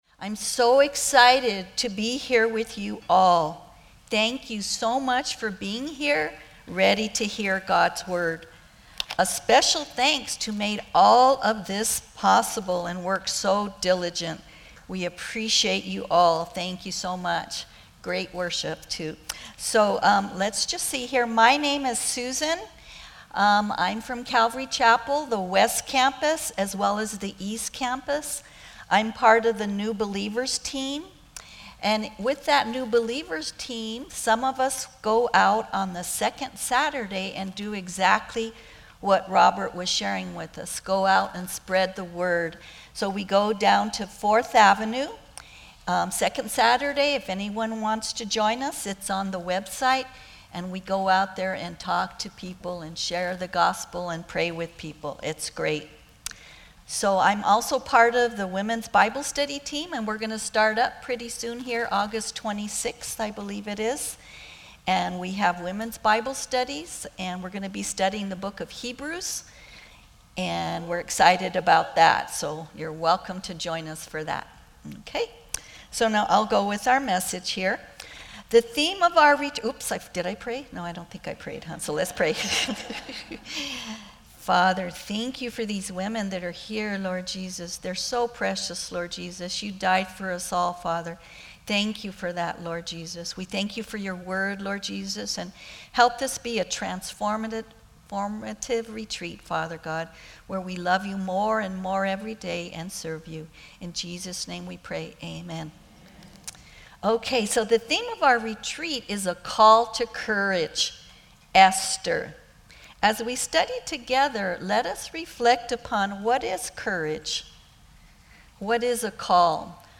Women's Retreat 2025 - Positioned for Purpose - Calvary Tucson Church